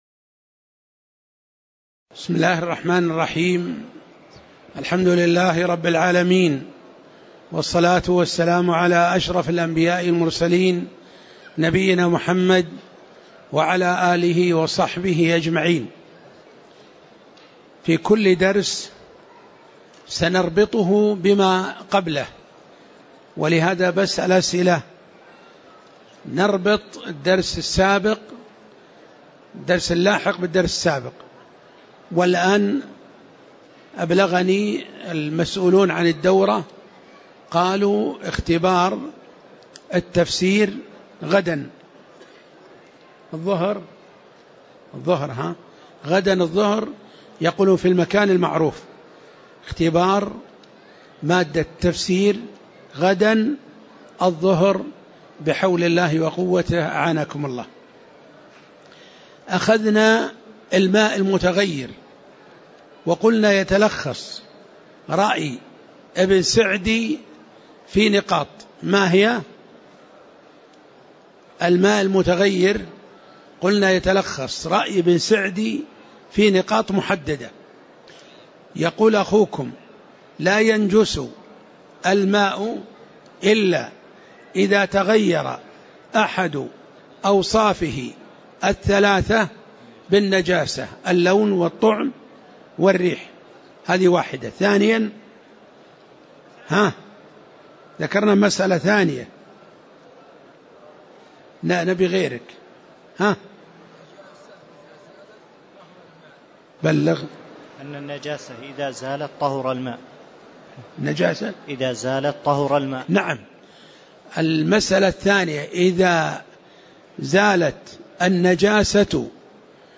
تاريخ النشر ١٤ شوال ١٤٣٨ هـ المكان: المسجد النبوي الشيخ